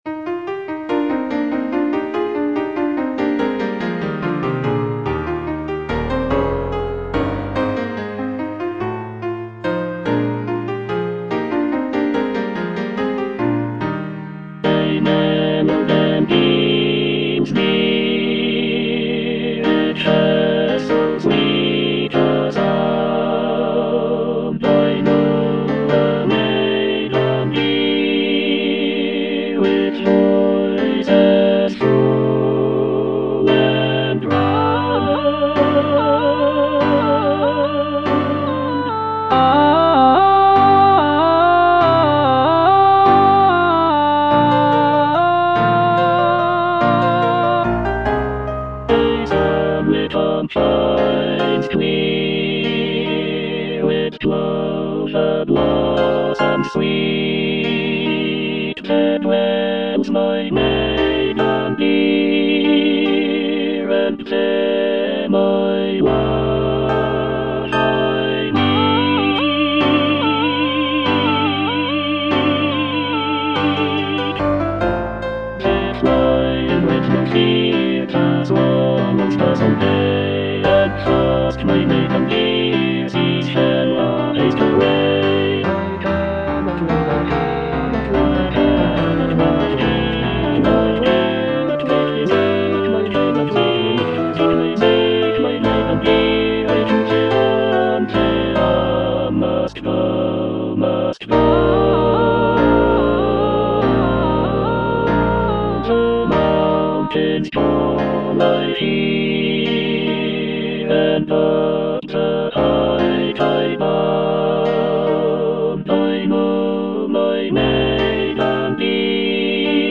E. ELGAR - FROM THE BAVARIAN HIGHLANDS On the alm (alto II) (Emphasised voice and other voices) Ads stop: auto-stop